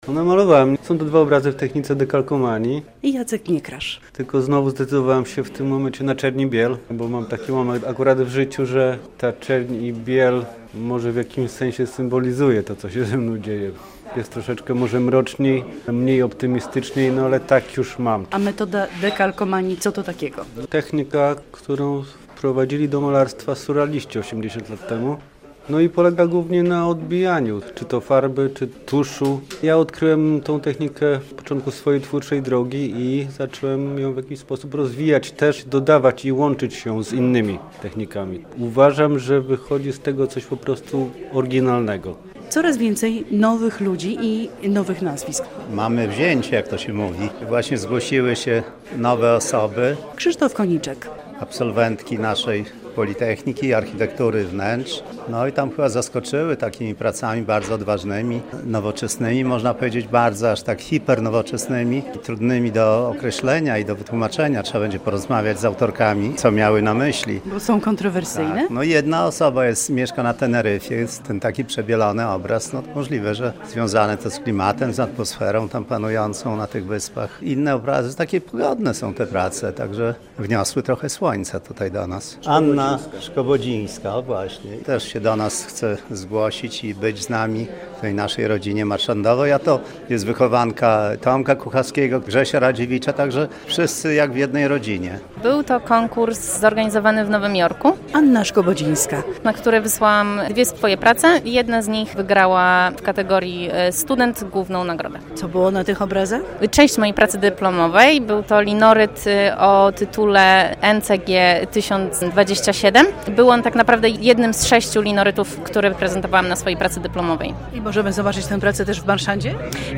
Jedno jest pewne - na wernisażu zawsze można spotkać i porozmawiać z malarzami.